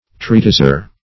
Treatiser \Trea"tis*er\, n. One who writes a treatise.